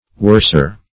Worser \Wors"er\, a.